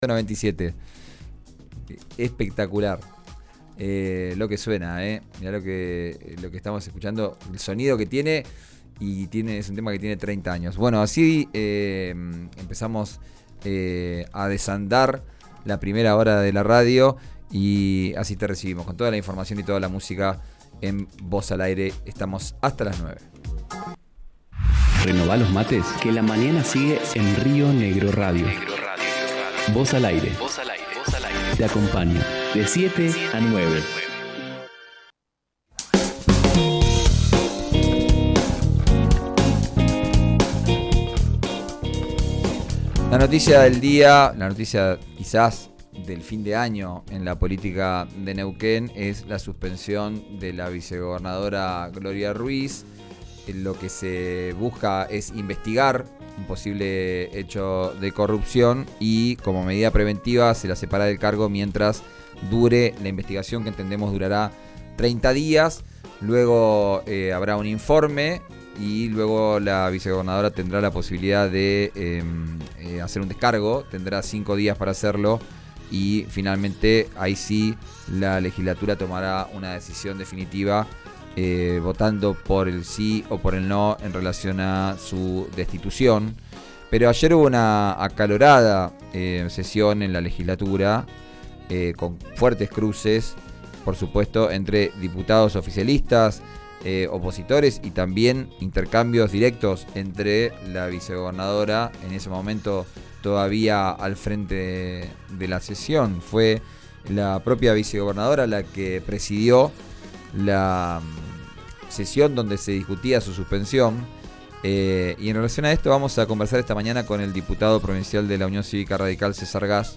El diputado provincial, César Gass, contó en RÍO NEGRO RADIO todos los detalles.
Escuchá al diputado provincial César Gass, en RÍO NEGRO RADIO